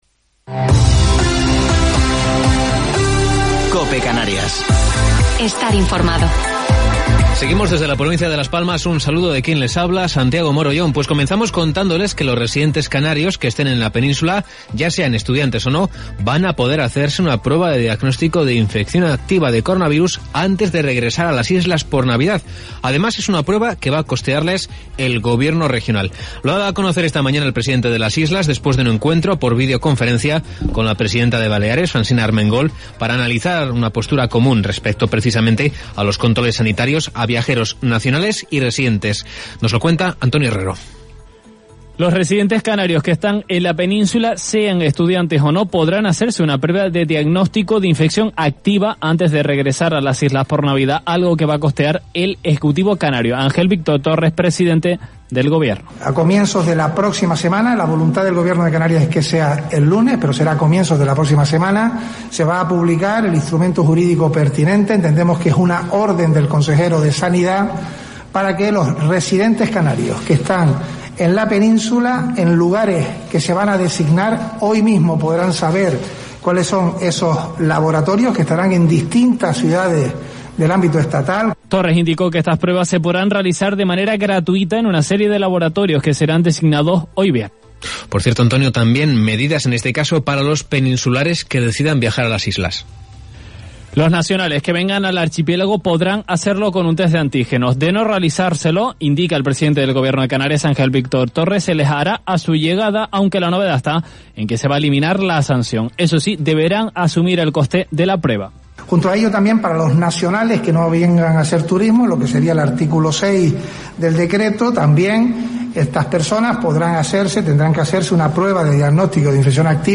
Informativo local 11 de Diciembre del 2020